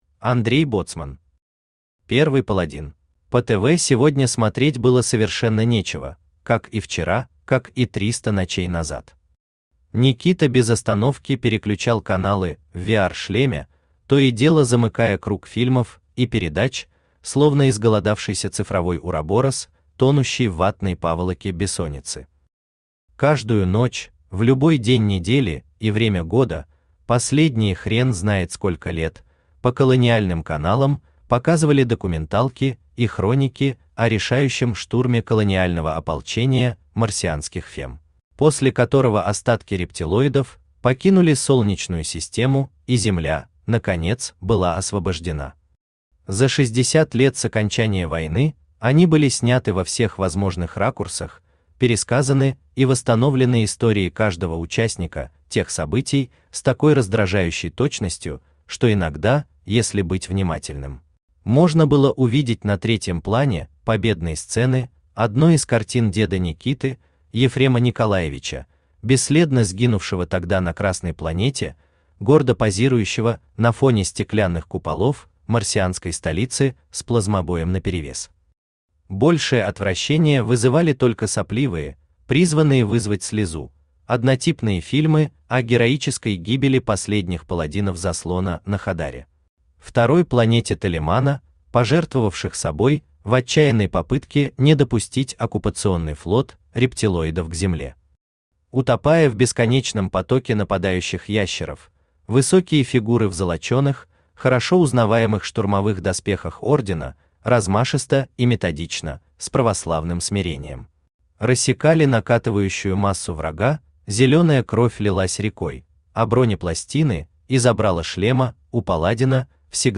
Аудиокнига Первый Паладин | Библиотека аудиокниг
Aудиокнига Первый Паладин Автор Андрей Боцман Читает аудиокнигу Авточтец ЛитРес.